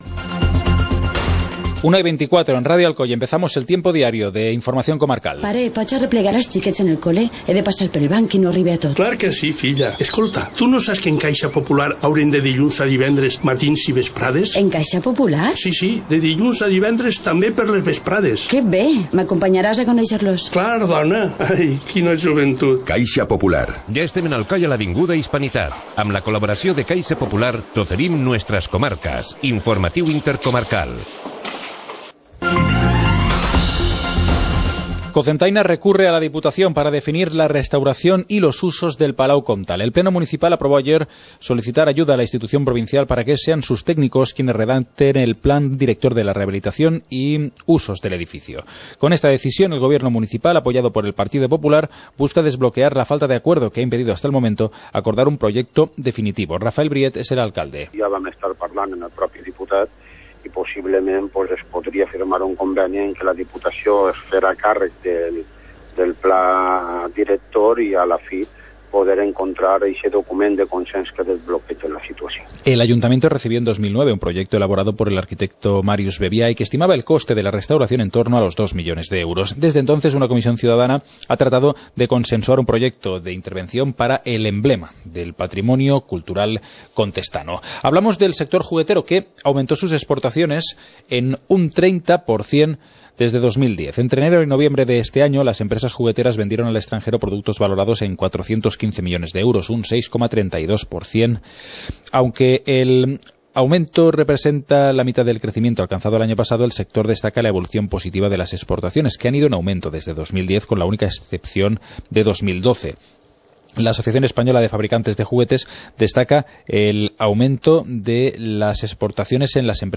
Informativo comarcal - viernes, 30 de enero de 2015